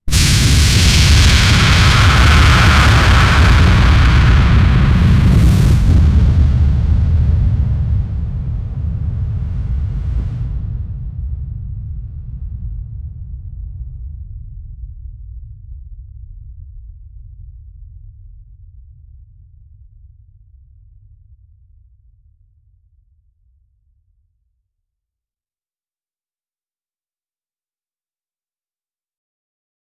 massive-ape-roar-echoing--yfzdjh77.wav